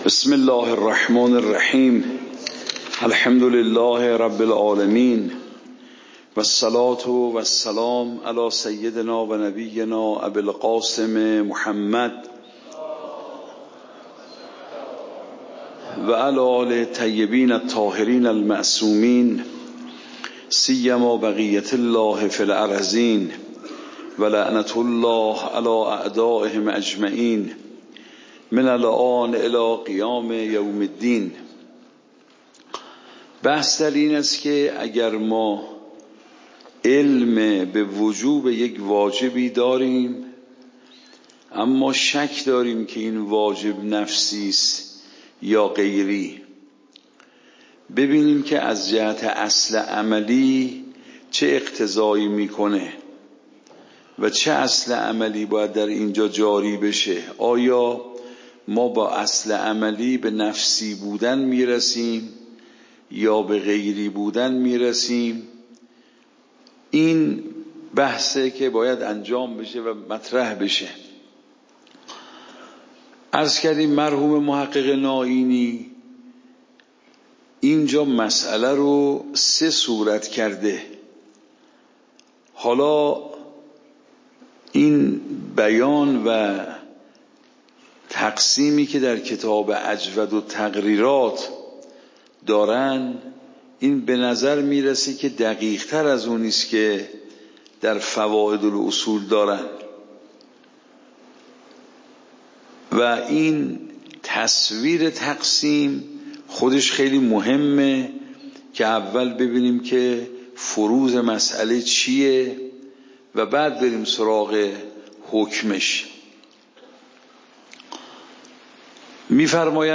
درس بعد واجب نفسی و غیری درس قبل واجب نفسی و غیری درس بعد درس قبل موضوع: واجب نفسی و غیری اصول فقه خارج اصول (دوره دوم) اوامر واجب نفسی و غیری تاریخ جلسه : ۱۴۰۴/۷/۱ شماره جلسه : ۸ PDF درس صوت درس ۰ ۲۶۶